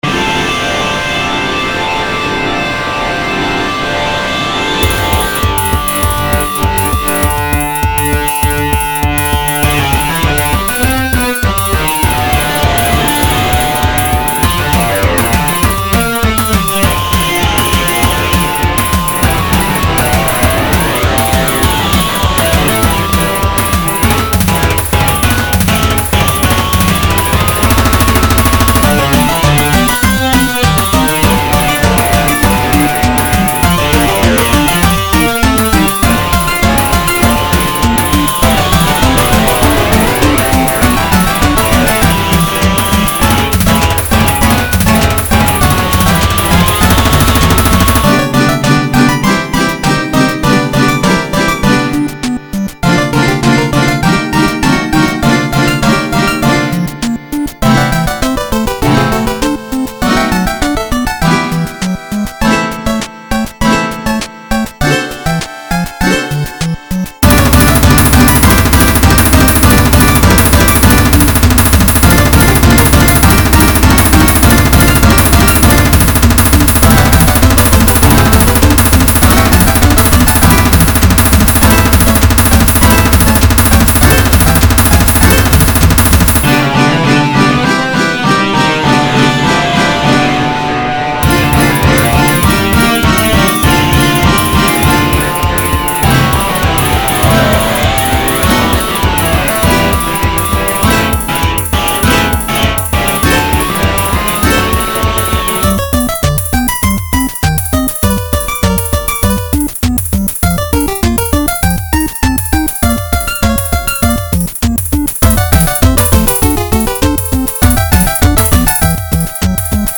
Distorted guitar and exciting drums FOR FUN!